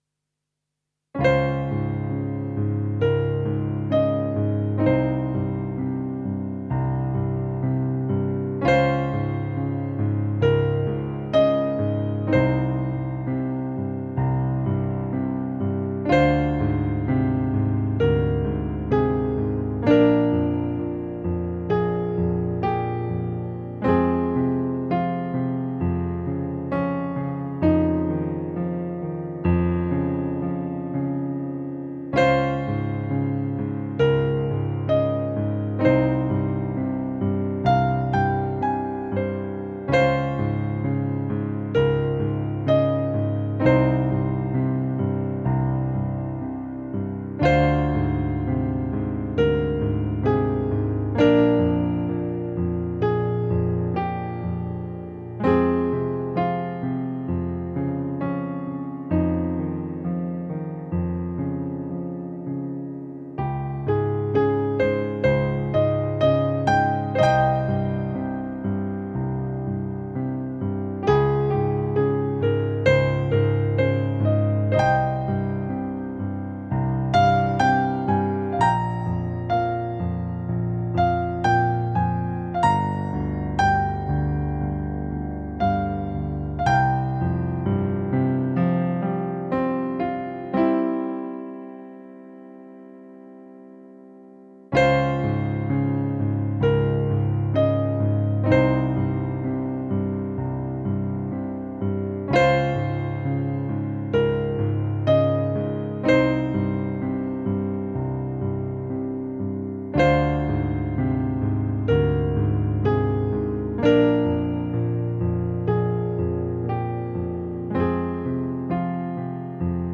冬空夜　オリジナル　ピアノソロ